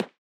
Walk02.wav